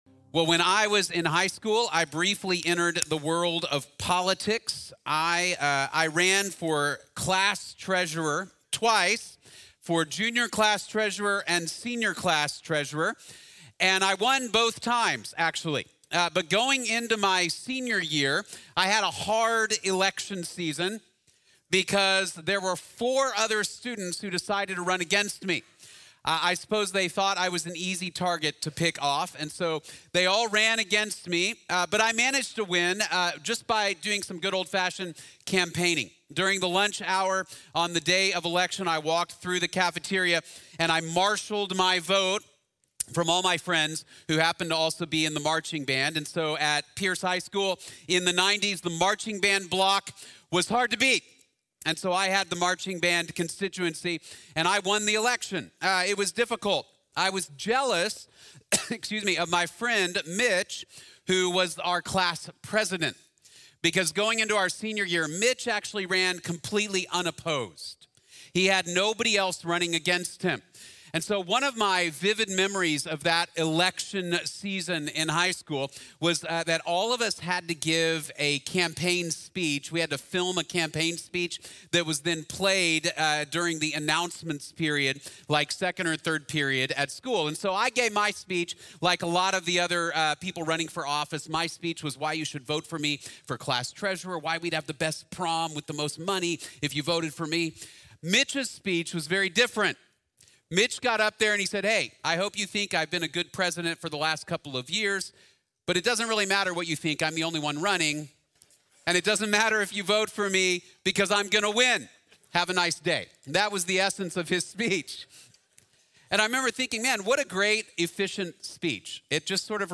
The Only True King | Sermon | Grace Bible Church